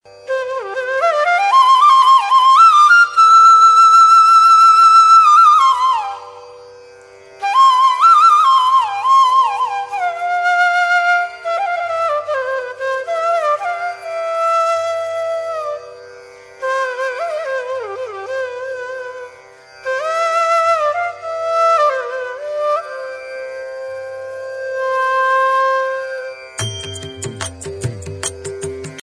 Красивая Флейта